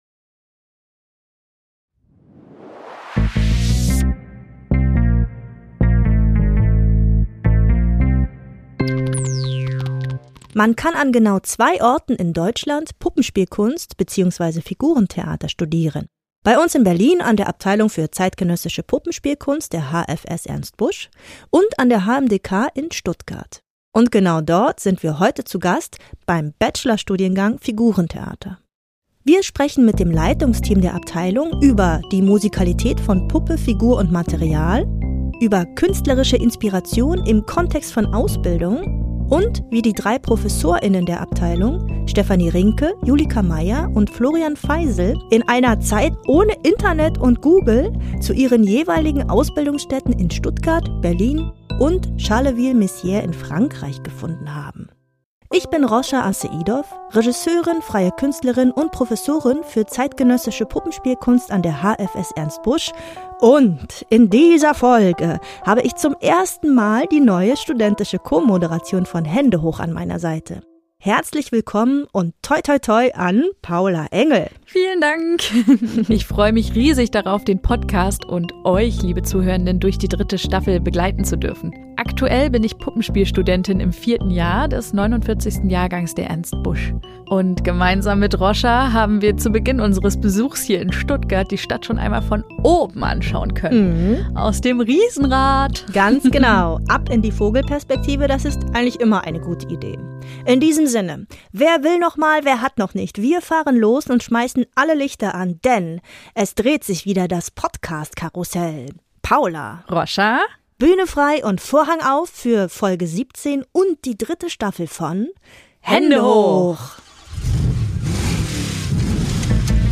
Viele spannende Gäste aus der Szene der zeitgenössischen Puppenspielkunst stehen Rede und Antwort.